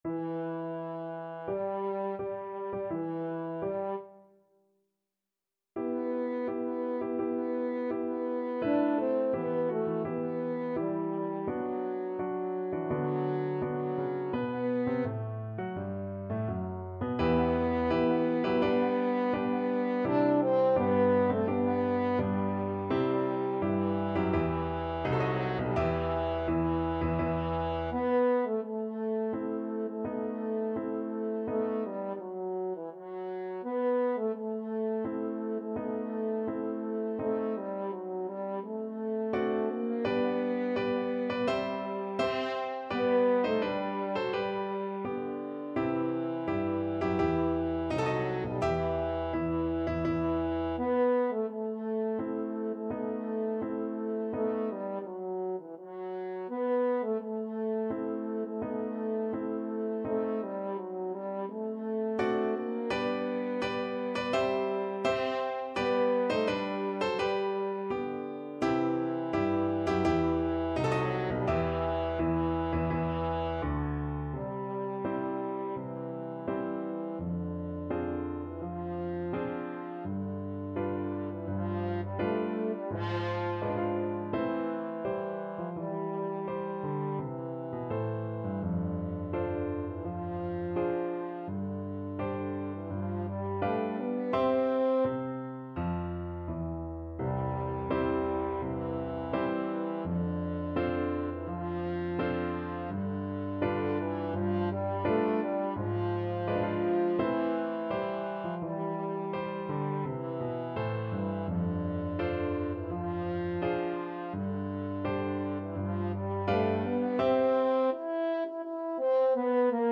4/4 (View more 4/4 Music)
Tempo di Marcia =84
Classical (View more Classical French Horn Music)